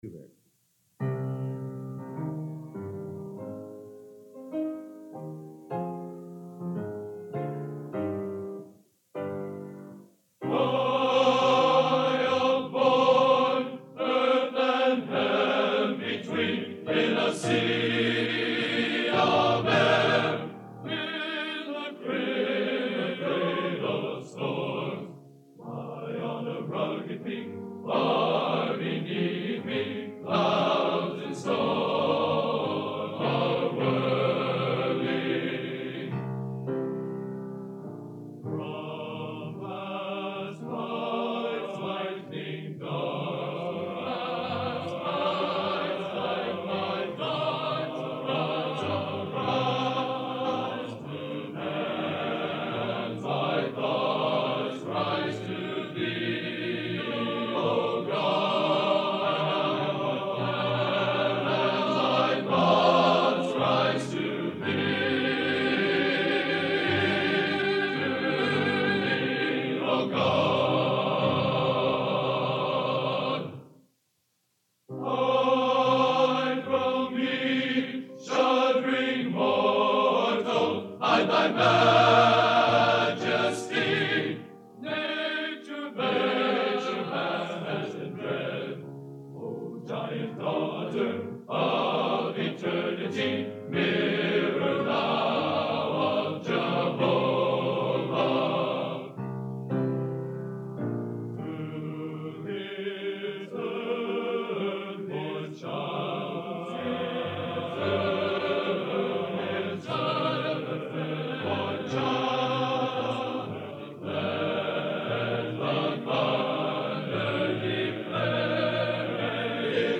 Genre: Classical Sacred | Type: End of Season